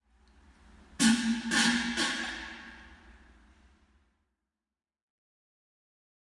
屁 " 真正需要放屁的人
描述：真正的屁与一些自然的混响。在一个恶心的搞砸的酒吧里，用一把乱糟糟的iPhone 7录制。一如既往，我喝醉了，在那里可爱的厕所放屁。
标签： 语音 混响 环境 酒吧 啤酒 放屁 声乐 恶心 万圣节
声道立体声